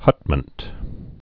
(hŭtmənt)